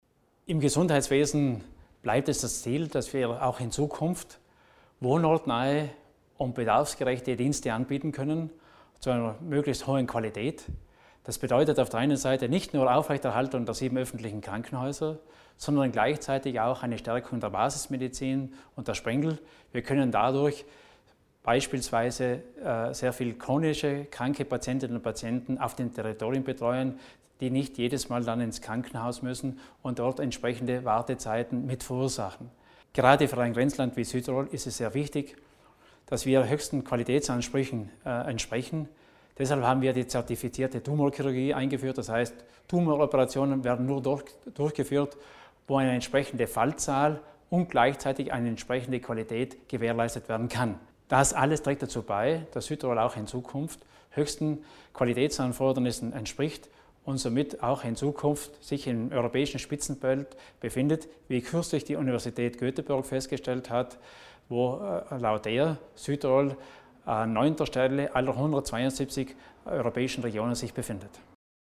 Landesrat Theiner zu den Zielen im Gesundheitswesen
Ein noch treffsichereres und gerechtes Sozialsystem hat Landesrat Richard Theiner heute (8. August) bei seinem Mediengespräch zum Legislaturende angekündigt. Und dazu drei Ziele für die Gesundheit, die wohnortsnah, bedarfsgerecht und hochwertig sein muss.